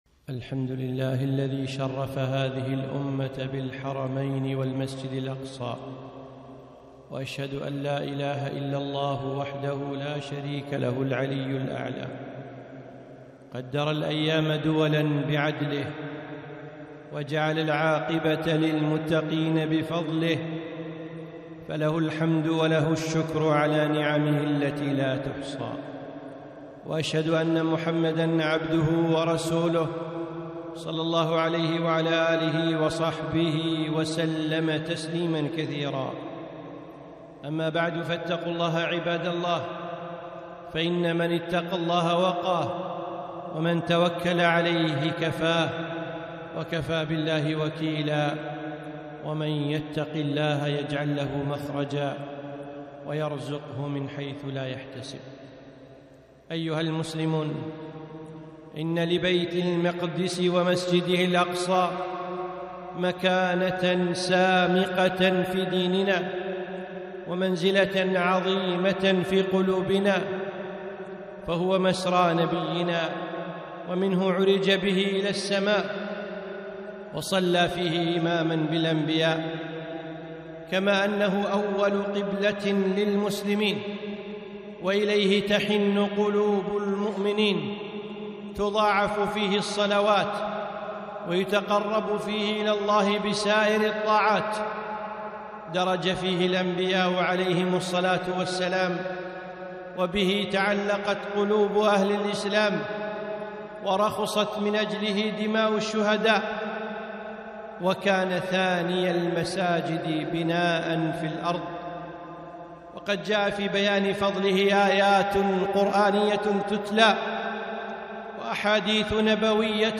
خطبة - المسجد الأقصى في قلوبنا